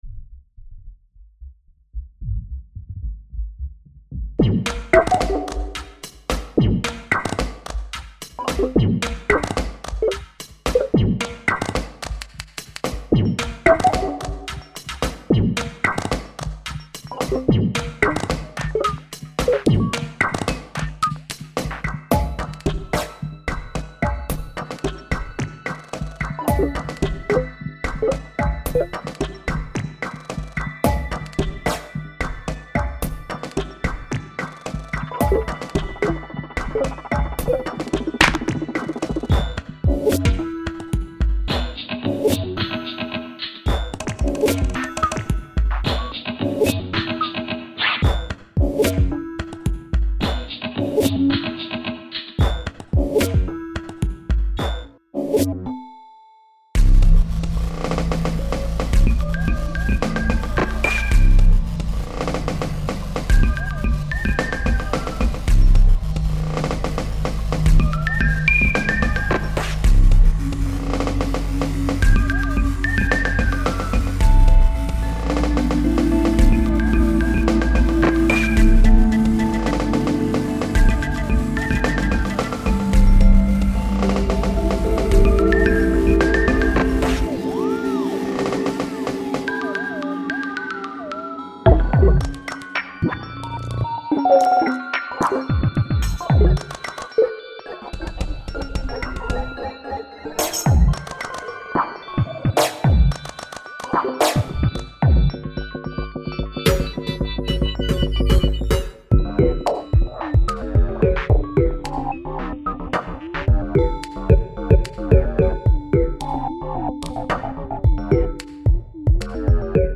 Experimental Minimal